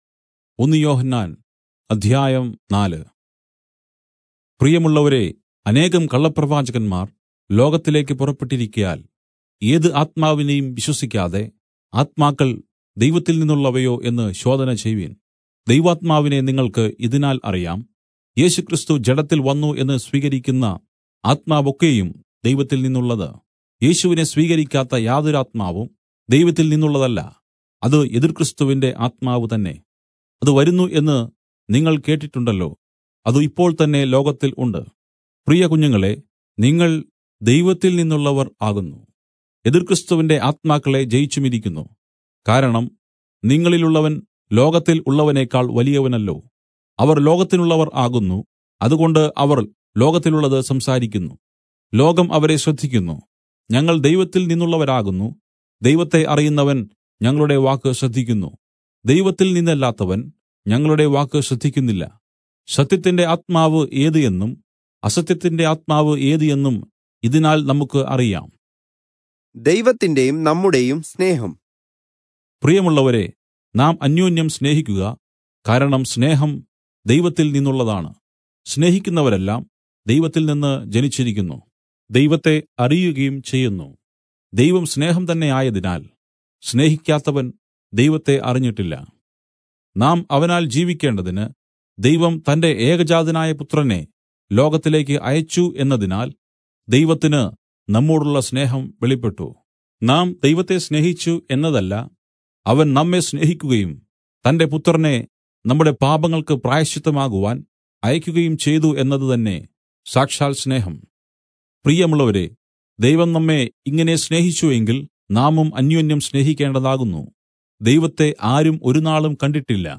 Malayalam Audio Bible - 1-John 5 in Irvml bible version